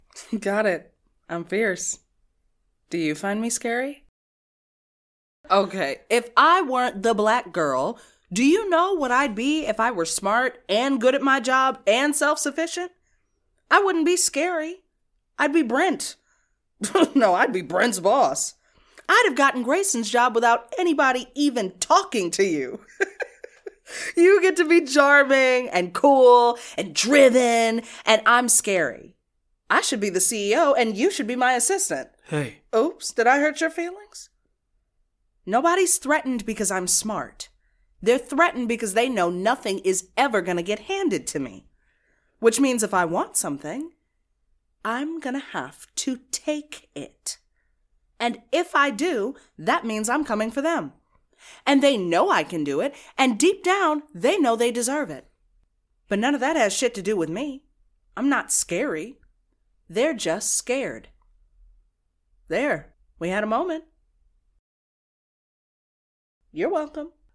Voiced here by a stellar complement of actors.